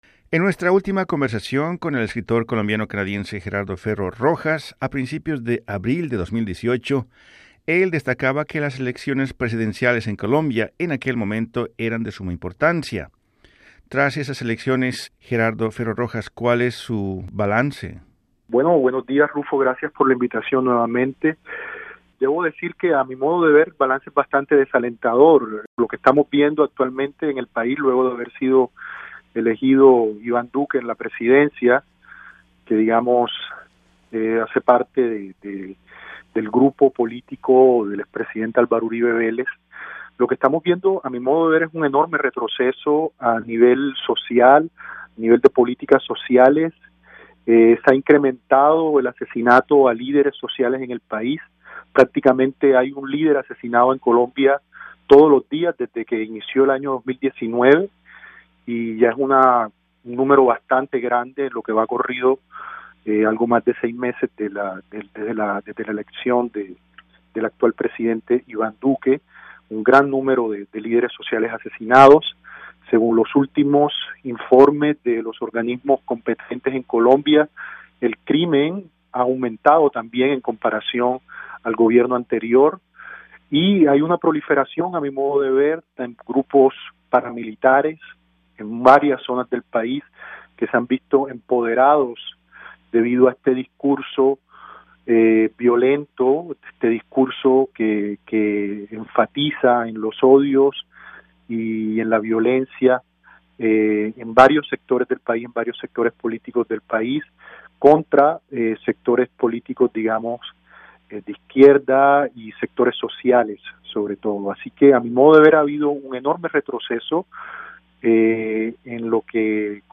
Entrevistado por Radio Canadá Internacional